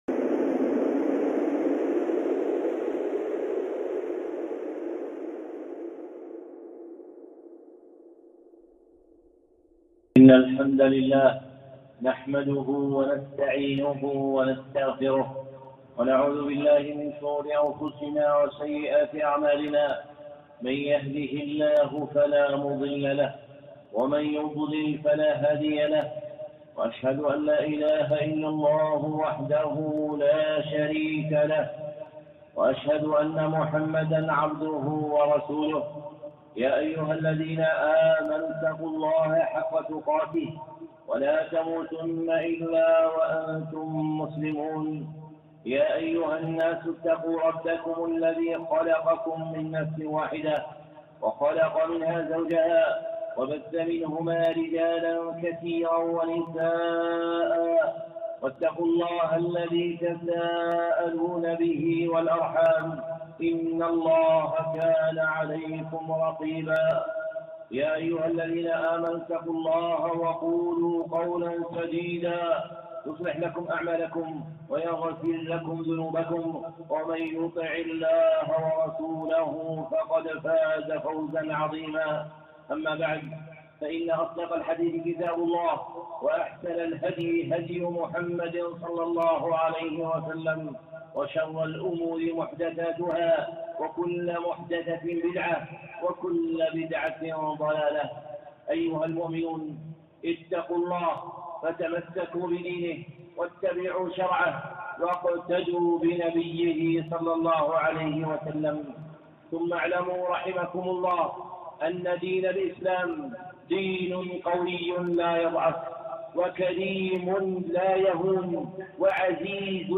خطبة (الإسلام يعلو ولا يعلى) الشيخ صالح العصيمي